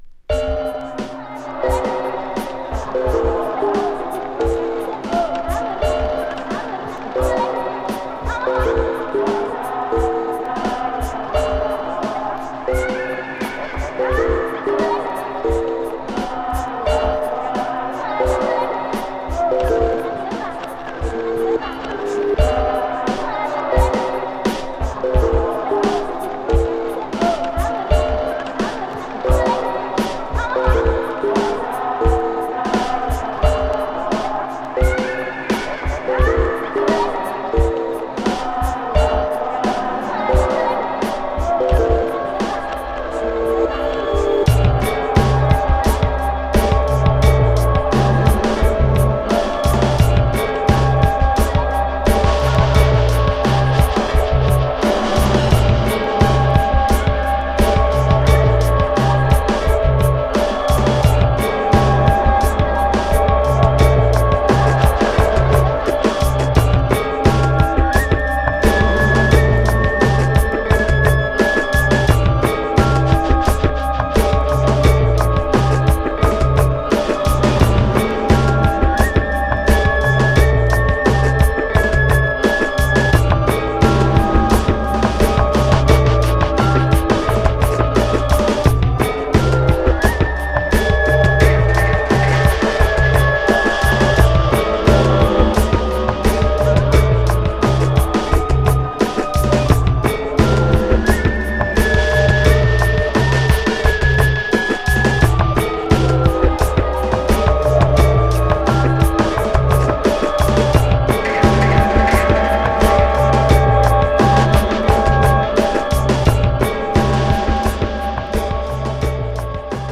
> JAZZY BREAK/ELECTRONICA/ABSTRACT